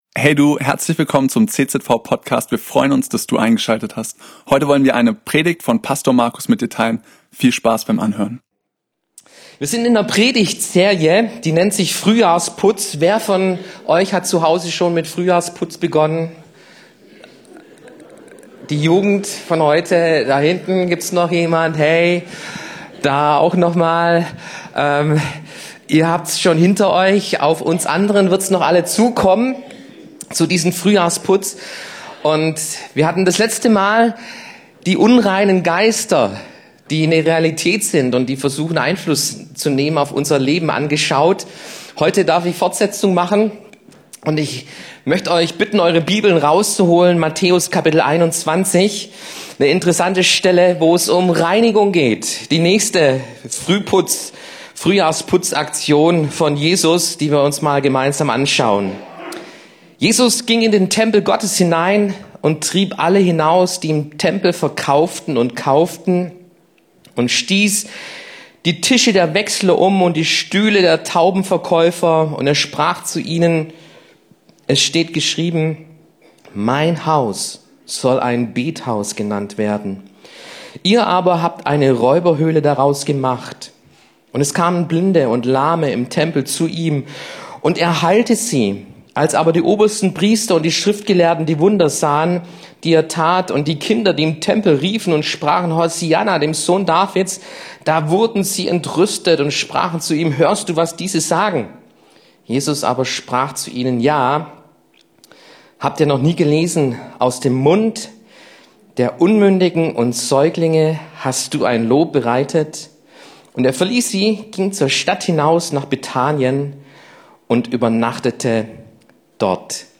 Fortsetzung unserer aktuellen Predigtserie